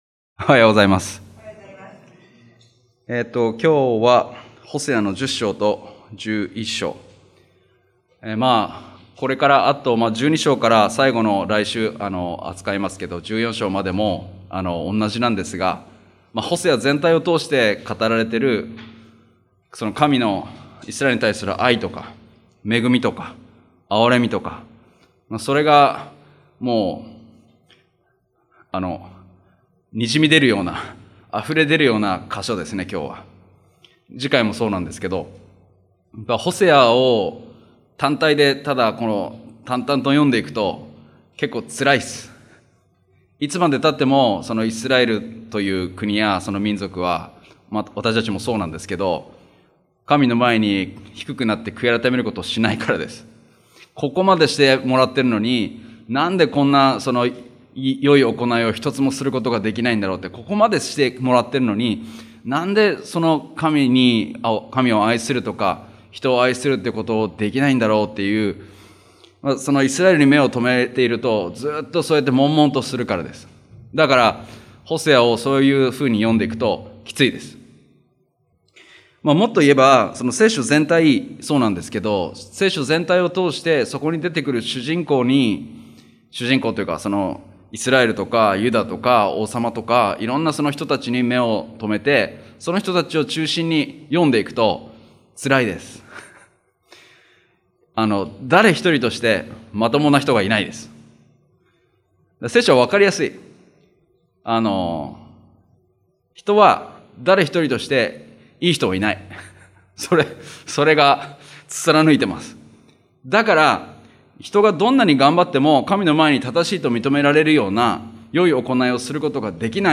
日曜礼拝：ホセア書
礼拝メッセージ